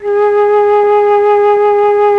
RED.FLUT1 14.wav